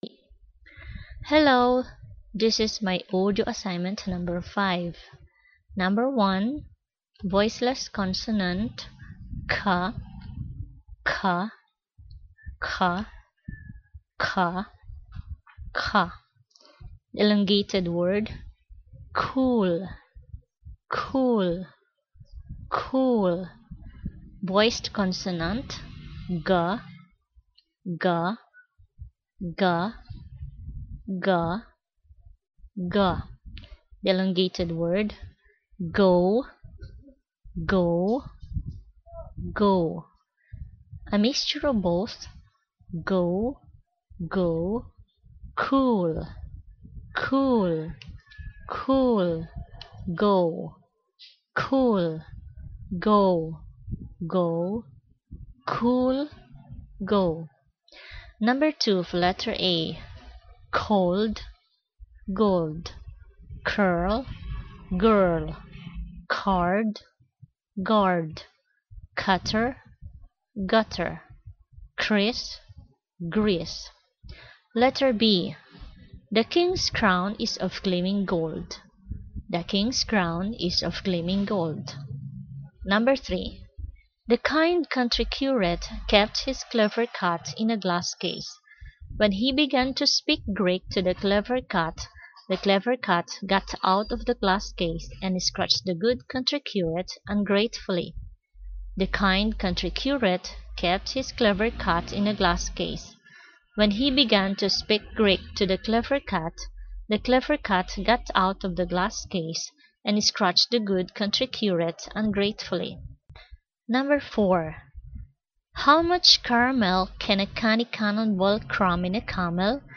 /k/ and /g/
phonemes